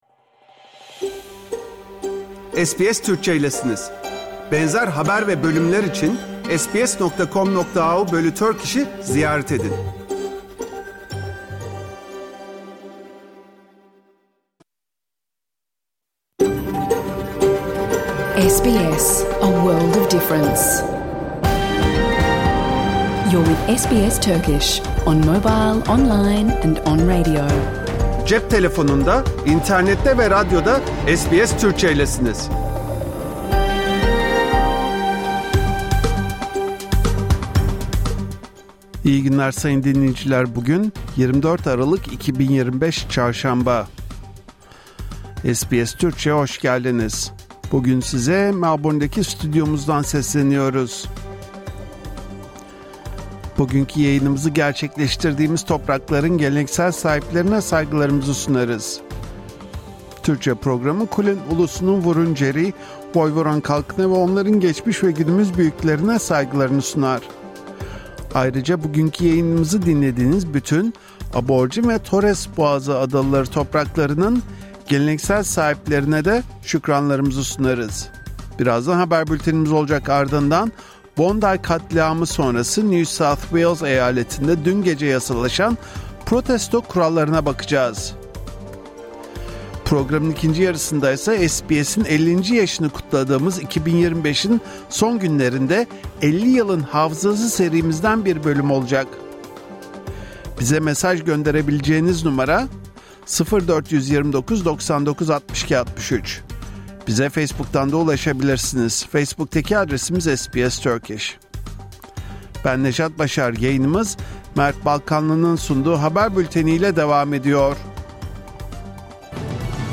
Hafta içi Salı hariç her gün Avustralya doğu kıyıları saati ile 14:00 ile 15:00 arasında yayınlanan SBS Türkçe radyo programını artık reklamsız, müziksiz ve kesintisiz bir şekilde dinleyebilirsiniz.
🎧 PROGRAM İÇERİĞİ HABER BÜLTENİ. Bondi saldırısının ardından acil olarak toplanan NSW parlamentosu protesto hakkını kısıtlayan bir tasarıyı yasalaştırdı.